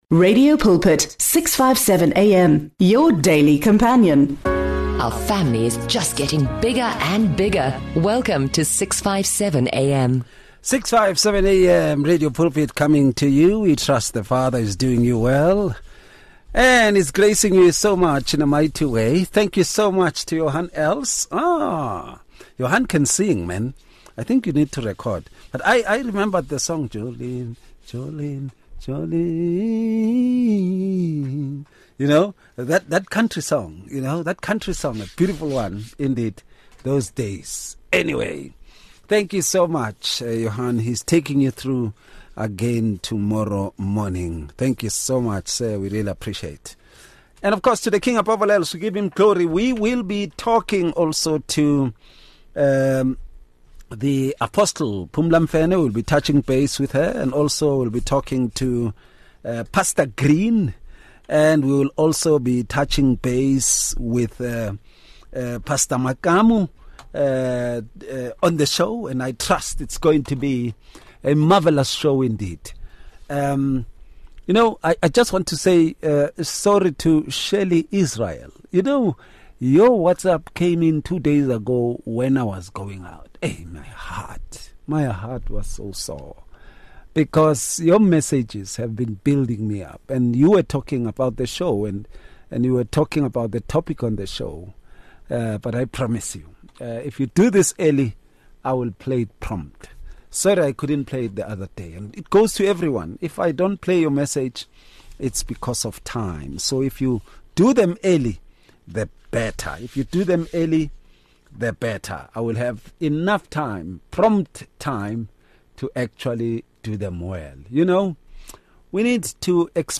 The panel emphasizes that such associations lead to moral compromise, spiritual vexation, and divine displeasure, as the ungodly influence can draw believers away from God’s commands. They highlight that partial obedience or tolerance of evil companions brings persistent challenges and hinders spiritual growth.